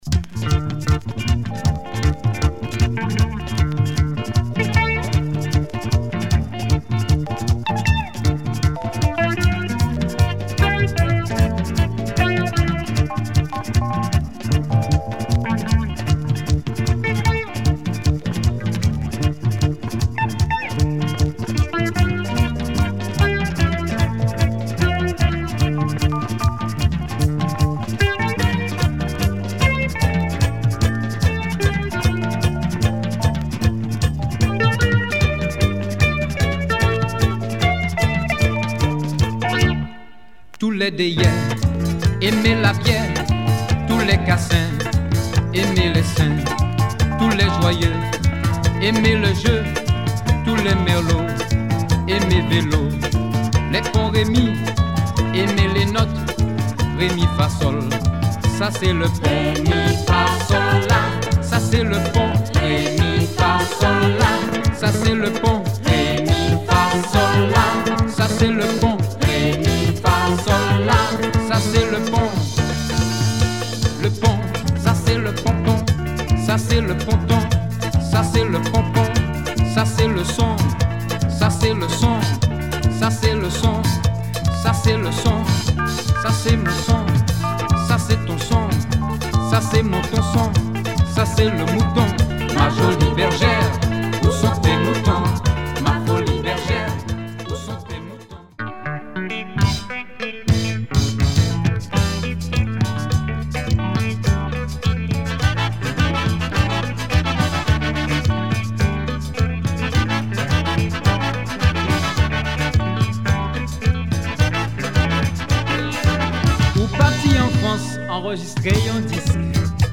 Compas, latin and jazz
sax